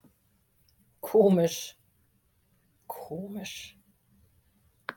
funny (amusing & strange) komisch (KO-misch)